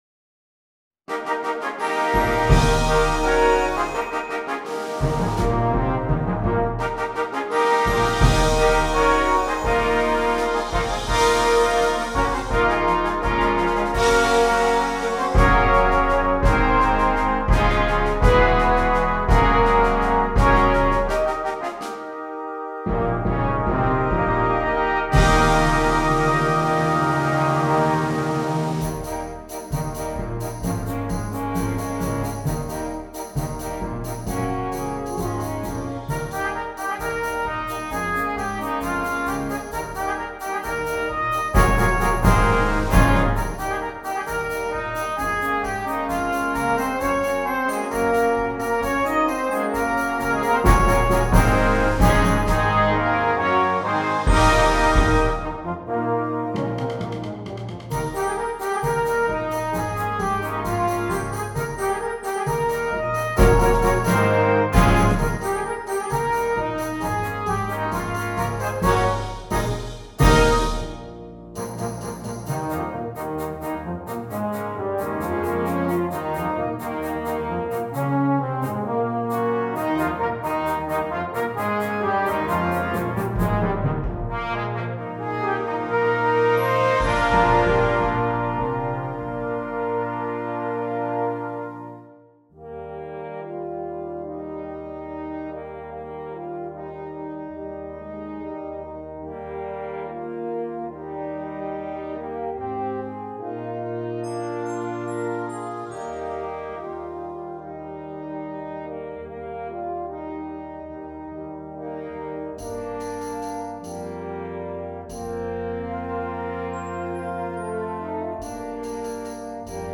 Voicing: 11 Brass and Percussion